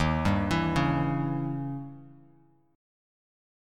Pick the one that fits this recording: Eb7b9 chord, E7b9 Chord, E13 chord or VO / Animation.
Eb7b9 chord